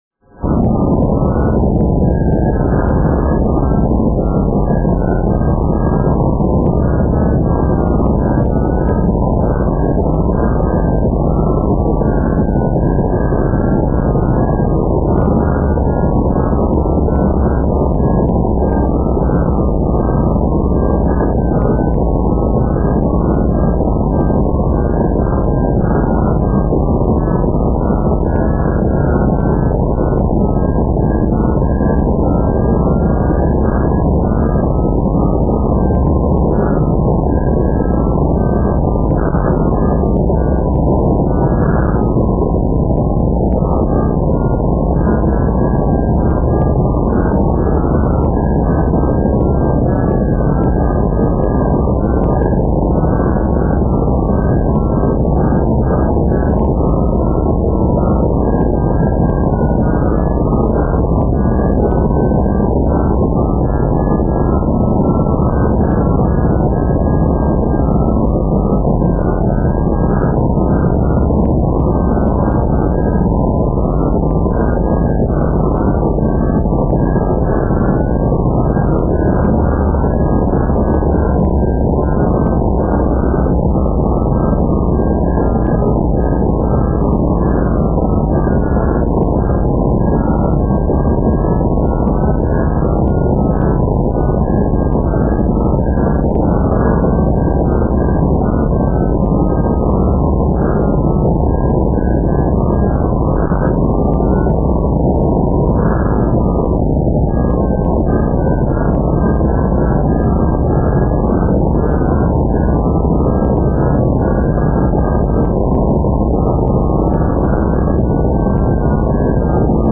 ambient lowbit